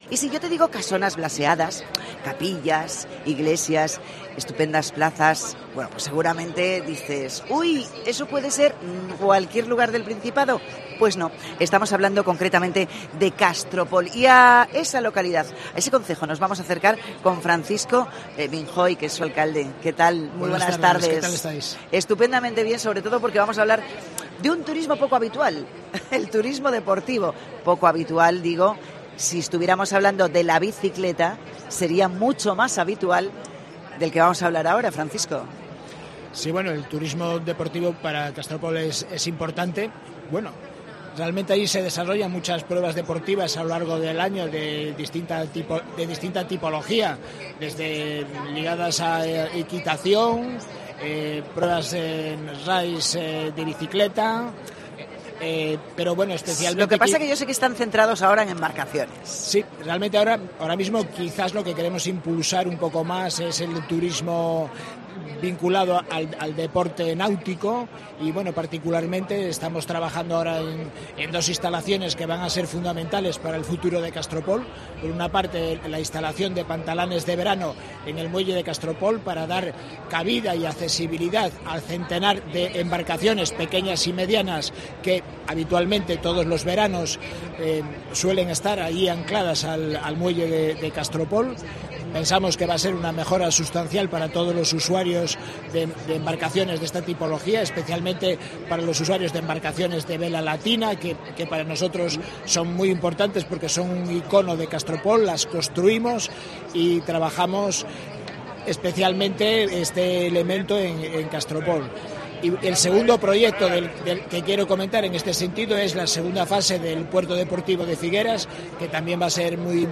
FITUR 2024: Entrevista a Francisco Vinjoy, alcalde de Castropol